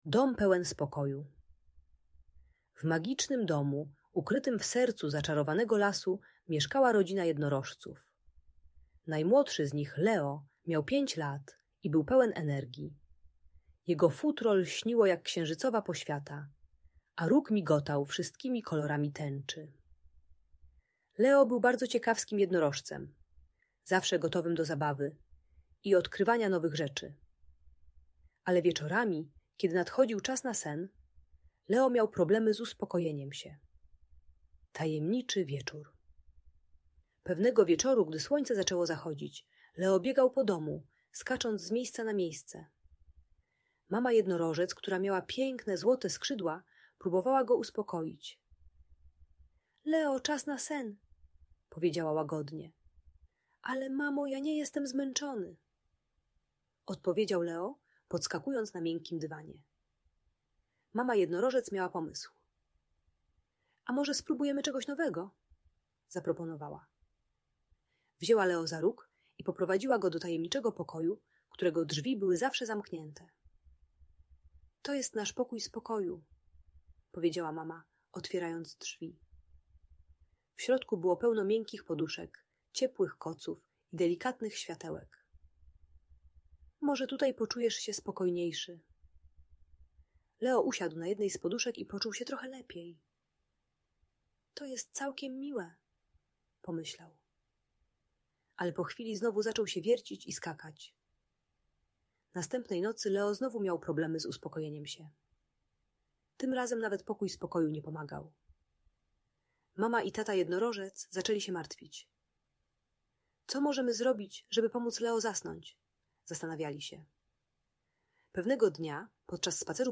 Tajemnicze Rytuały Spokoju - Usypianie | Audiobajka